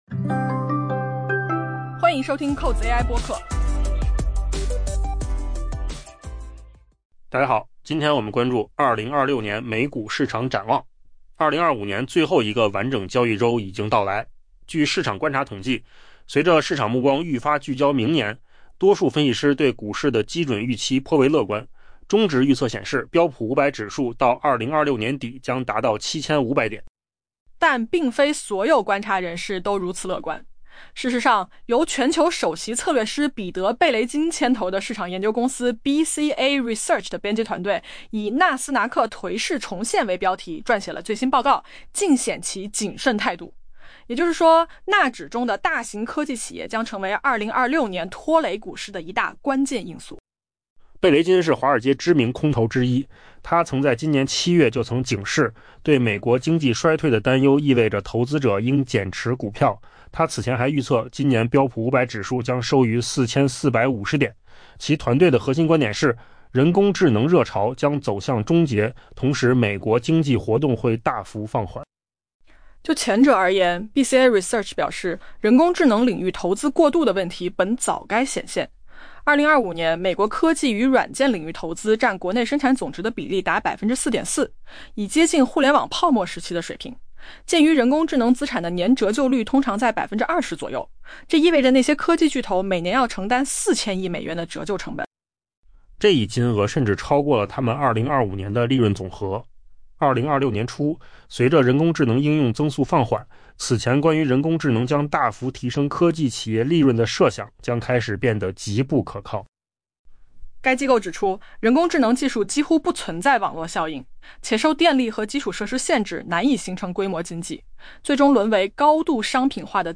AI 播客：换个方式听新闻 下载 mp3 音频由扣子空间生成 2025 年最后一个完整交易周已经到来。